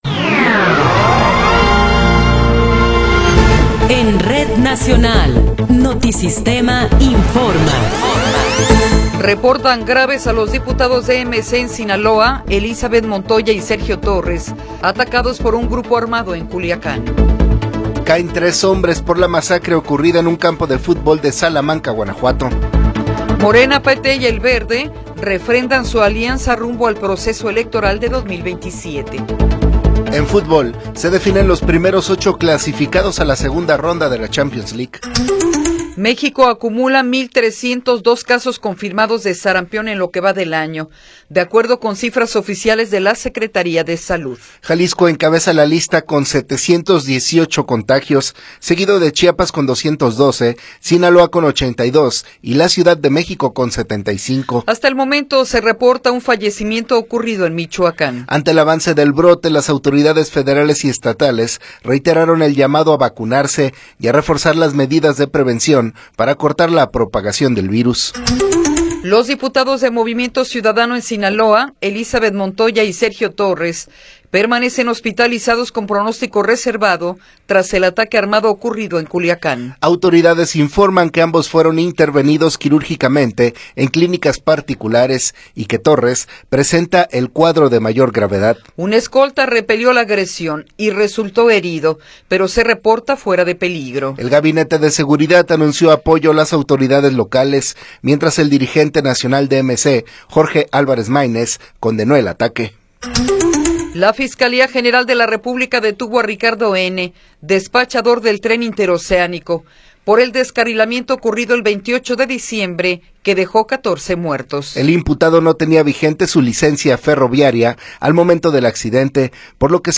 Noticiero 8 hrs. – 29 de Enero de 2026
Resumen informativo Notisistema, la mejor y más completa información cada hora en la hora.